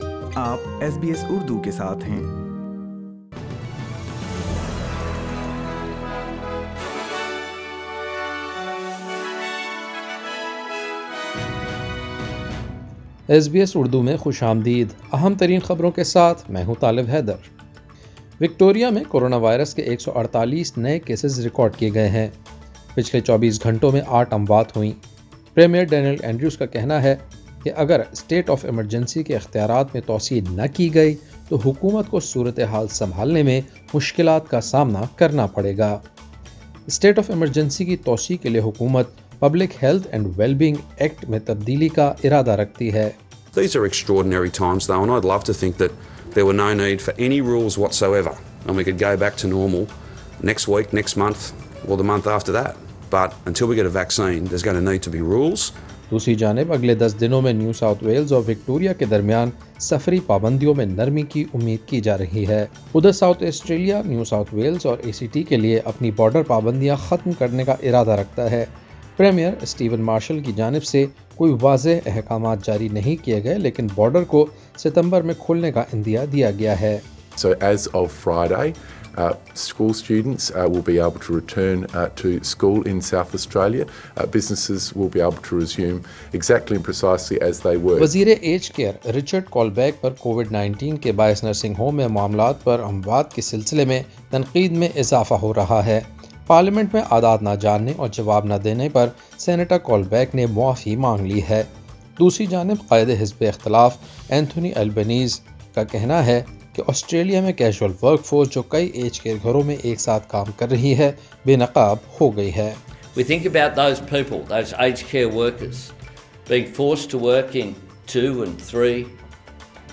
وکٹوریہ میں 'اسٹیٹ آف ایمرجنسی' میں توسیع کی کوششیں، نیو ساوتھ ویلز اور وکٹوریہ کے درمیان سفری پابندیوں میں نرمی متوقع اور قنٹاس کا مزید ملازمتوں کو ختم کرنے کا اعلان۔ سنیئے آسٹریلوی خبریں اردو میں۔